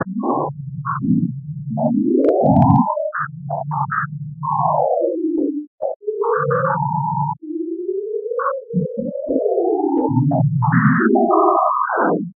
Coagula is a bitmap to sound converter.
Output : 44 kHz 16-b stereo WAV